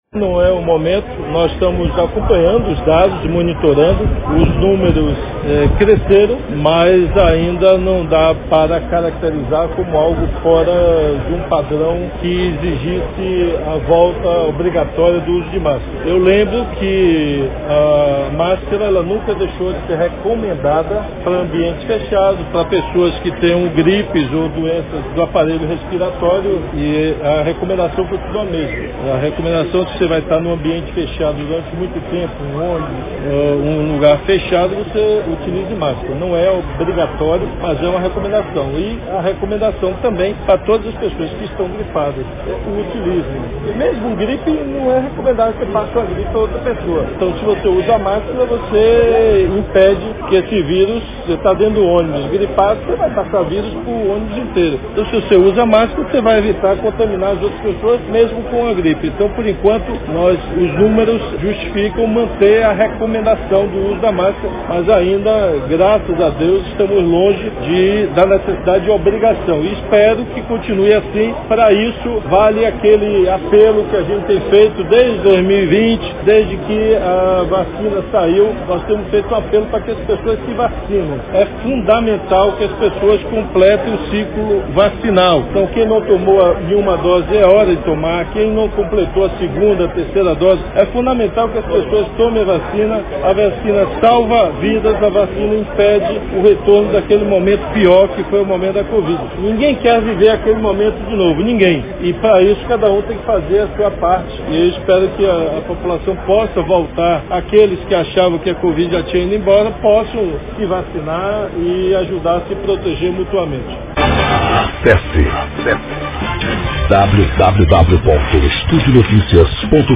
Confira o que disse o governador Rui Costa- PLay